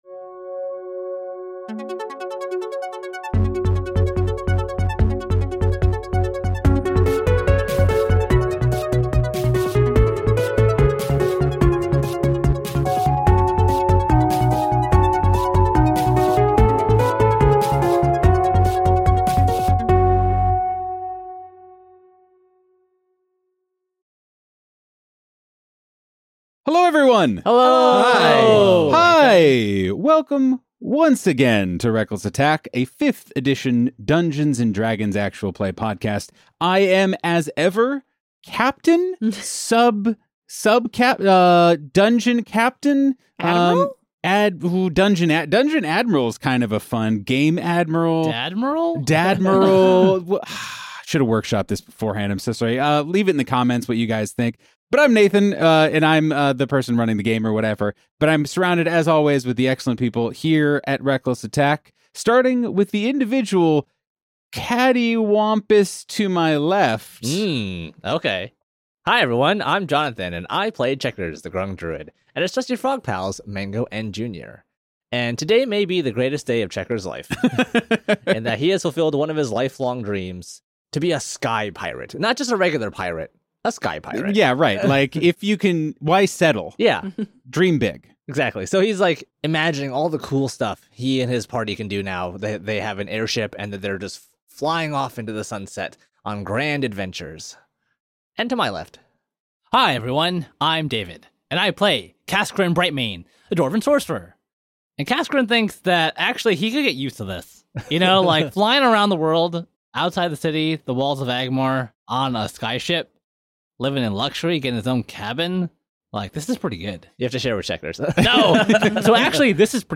Reckless Attack is a Dungeons and Dragons 5th Edition Real Play podcast, hosted by a group of cheery, diverse, regular folks in Chicago who love their game and want to share it with you.